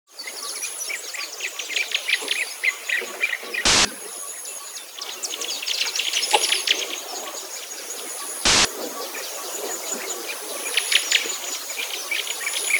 Поскольку в оригинальной исследовательской работе в качестве птичьего пения для своих тестов использовались песни скворцов, я нашел эту песню скворцов на Splice Sounds, а затем вставил всплески белого шума длительностью 200 мс со случайными интервалами по всему треку.